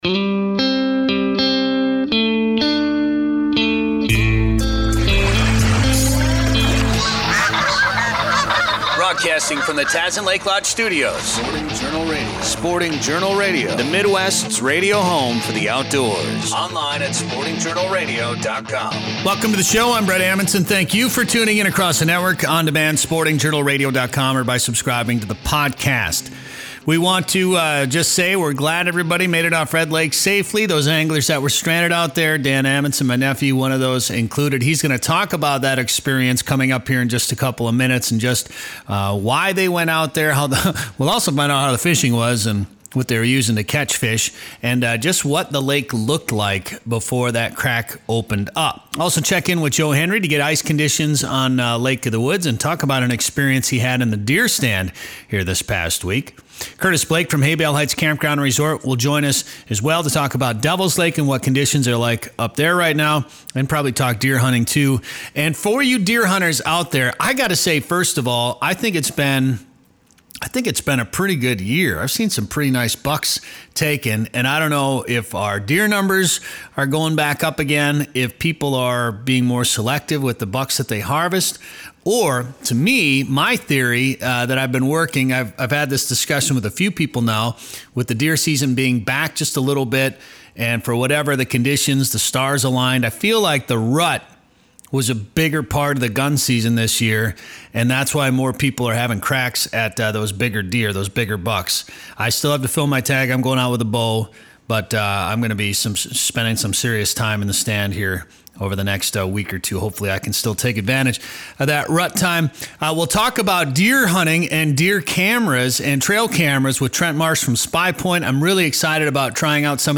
Show #375: Rescue on Red Lake Interview, Trail Camera Innovations and ice conditions.